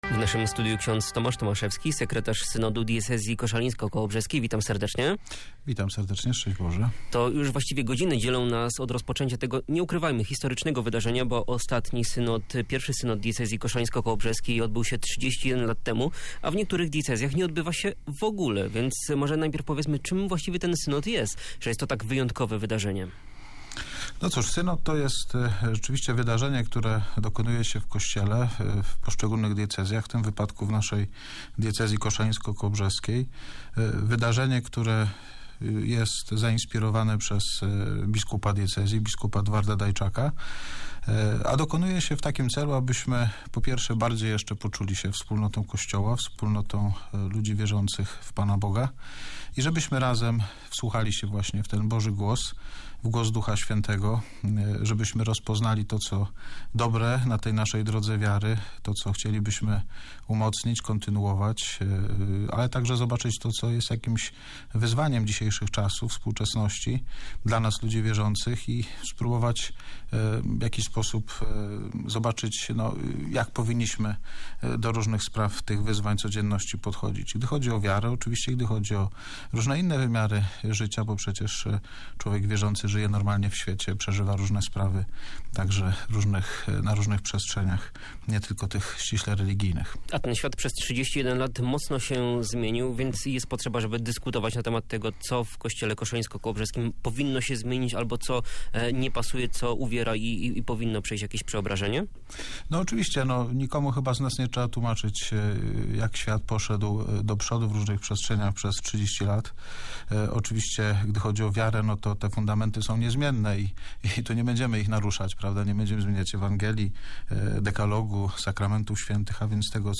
Rozmowa na temat synodu